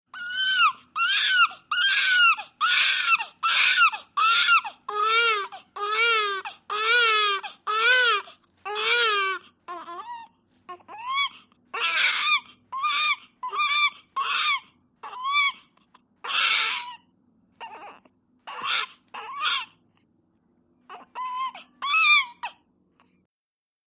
BABY ONE DAY OLD: INT: High pitched crying, short gasps.